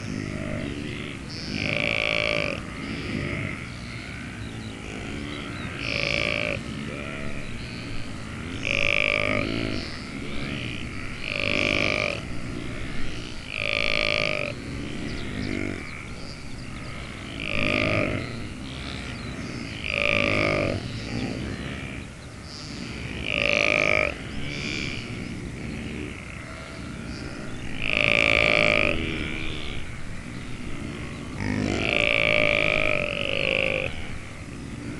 Звук Сайга - Рев среди тишины